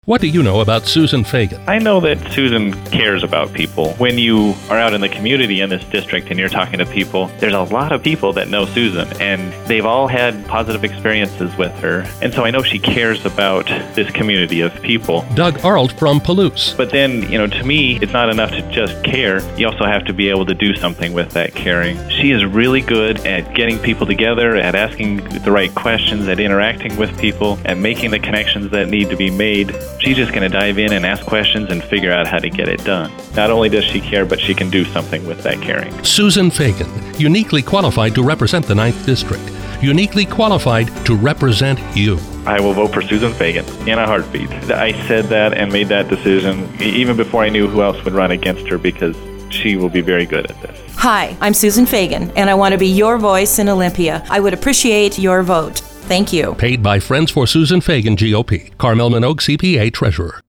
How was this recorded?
Rather, I chose to interview – in person or over the phone – the people whose recommendations might resonate with voters.